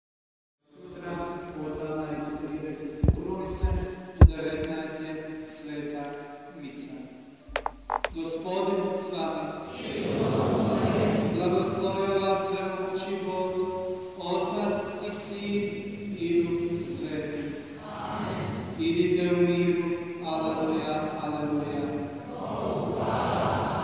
PROPOVIJED – USKRSNI PONEDJELJAK